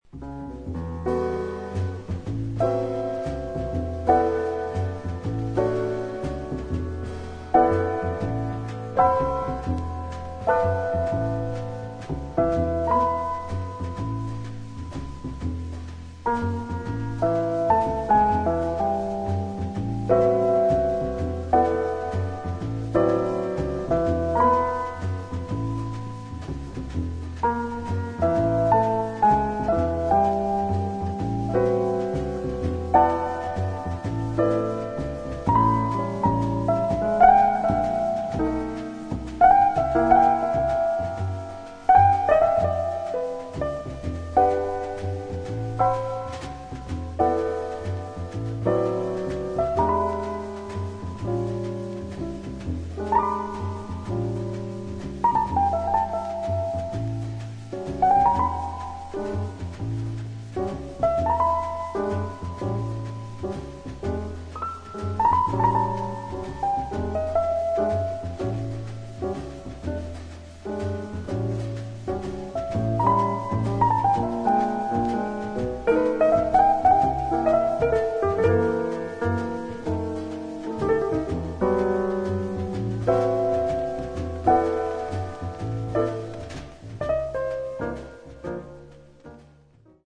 ジャズやブルースをベースにしつつも、宗教音楽やアヴァンギャルドな要素が含まれる唯一無二のアルバム。